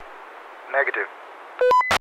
Tag: 导频 语音 战斗 男子 无线电 飞机 样本请求 雄性